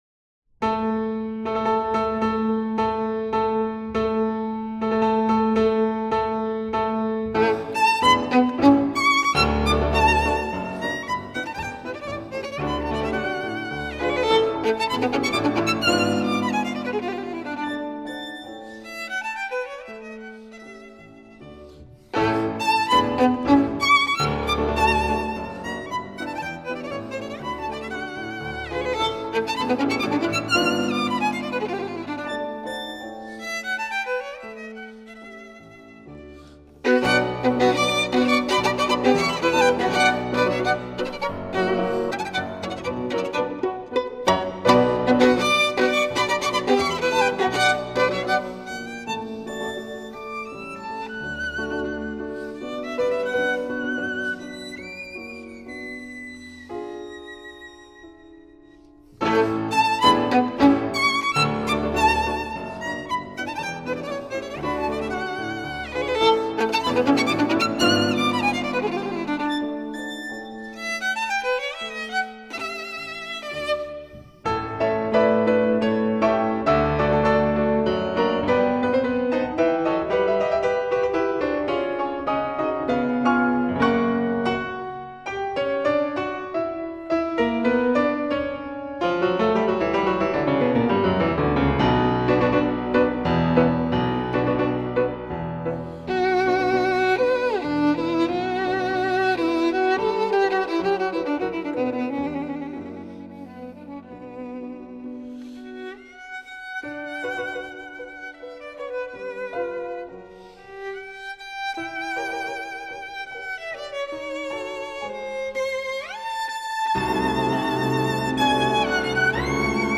小提琴与乐队